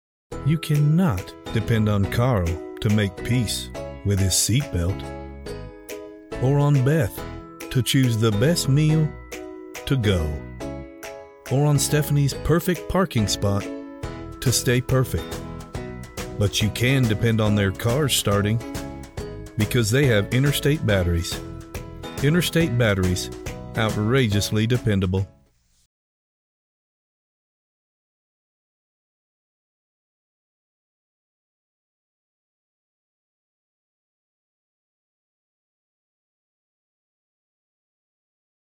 I am an American male voice-over artist with a naturally strong, clear voice with a broad range. My voice is primarily warm and smooth, yet authoritative with an intellectual subtleness.
All of my work is done in a professional sound booth, equipped with a Sennheiser MKH 416 Microphone – Nuemann TLM 103 Microphone - Yamaha Sound Board – PC with Adobe Audition – Source Connect.
Commercial